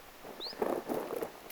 hyit-tiltaltin ääni